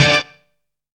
CRUSTY HIT.wav